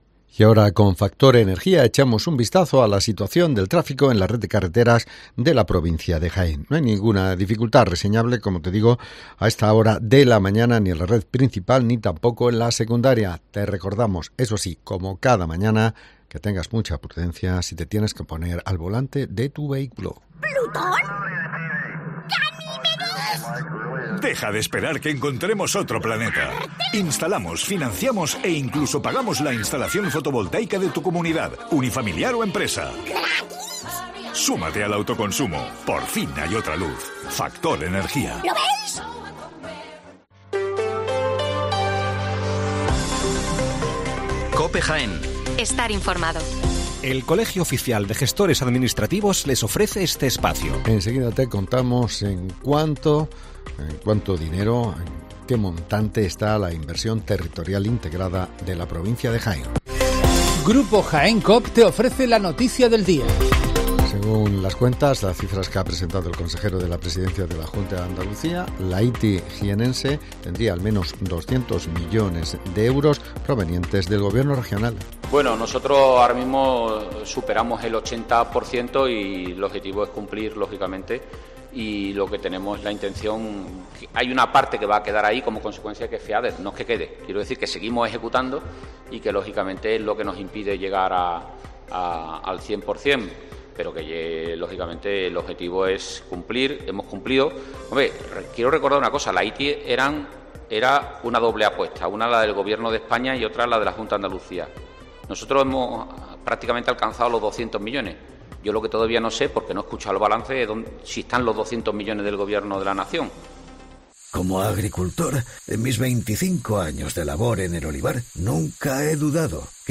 Las noticias locales de las 7'55 horas del 3 de octubre de 2023